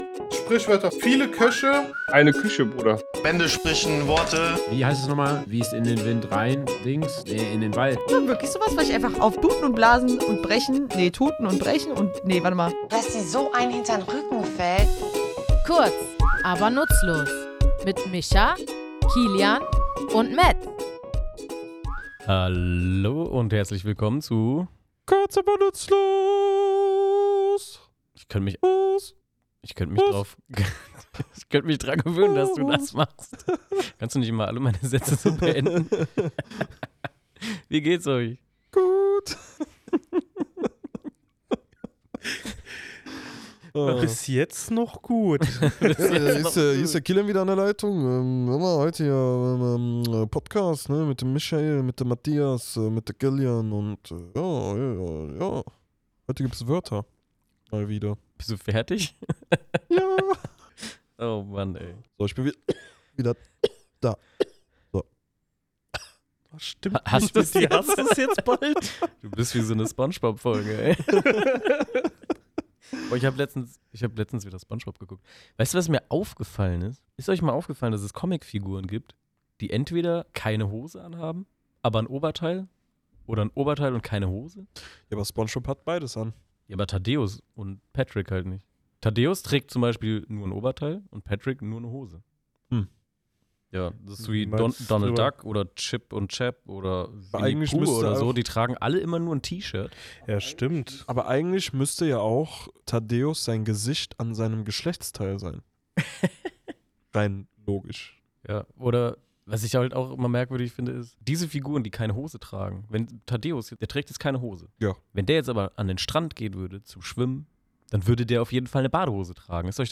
Wir, drei tätowierende Sprachfans, klären in unserem Tattoostudio die Bedeutung und Herkunft dieses Ausdrucks und werfen einen Blick darauf, wie Sprache die Arbeitswelt prägt.